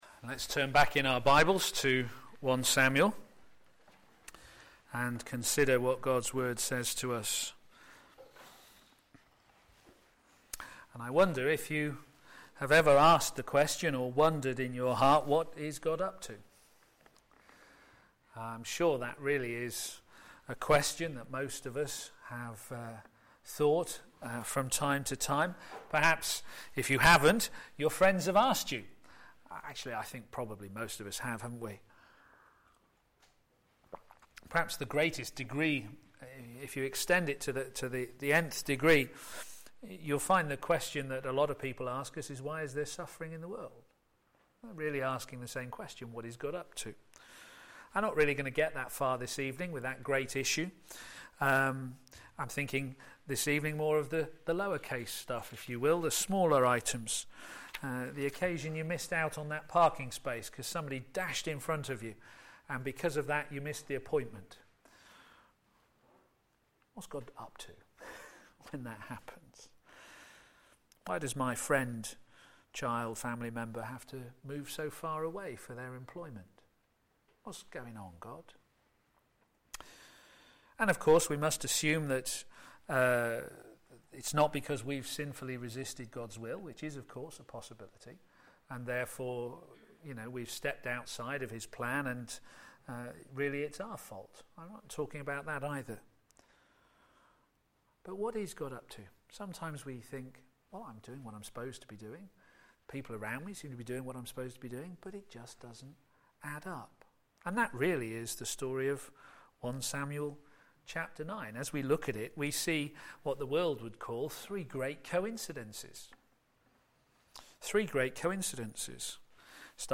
p.m. Service
Series: Samuel: Prophet of God's Grace Theme: Saul, Hidden providence Sermon